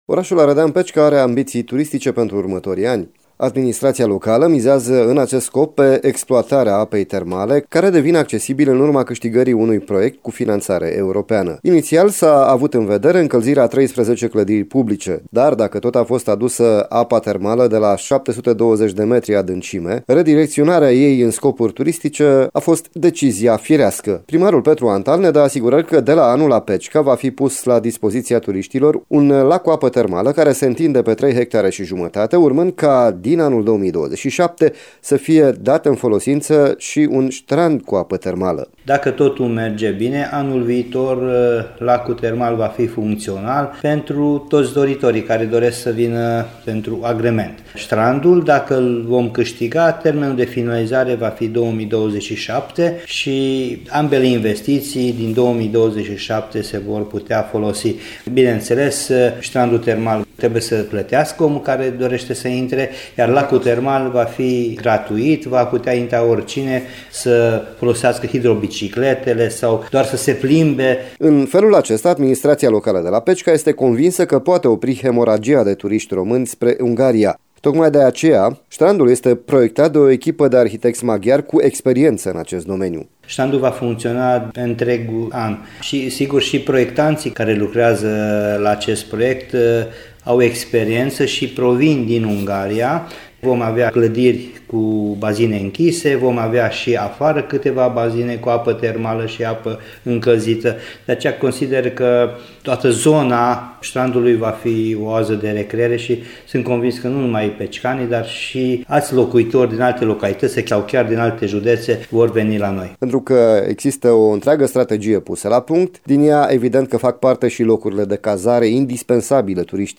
Primarul Petru Antal dă asigurări că de la anul, la Pecica, va fi pus la dispoziția turiștilor un lac cu apă termală care se întinde pe 3,5 hectare, urmând ca din anul 2027 să fie dat în folosință și un ștrand cu apă terminală.